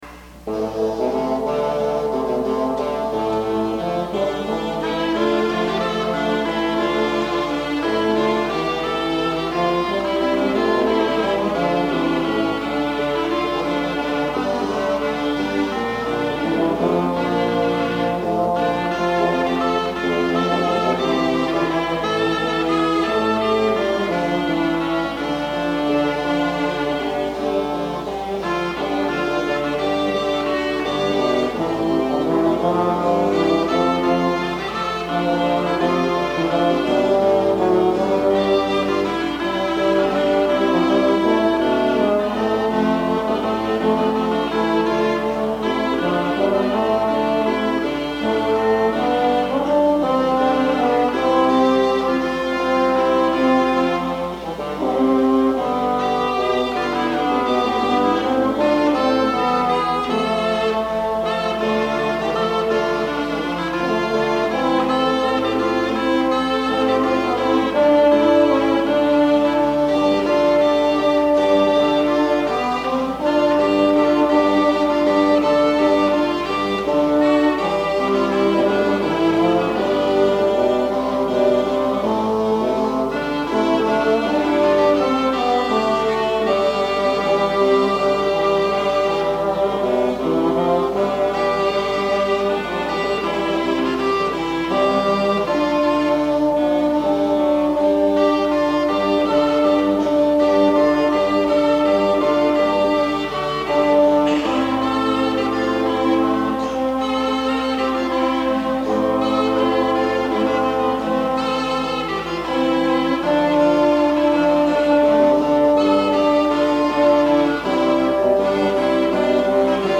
The tune of La spagna originated as a basse danse in the 15th century . . the dance is a slow and courtly in triple meter.  Josquin adds four other instruments to the basse danse tune in a rather dense polyphonic texture.  In this performance the tune is played on the slide trumpet (sackbut!).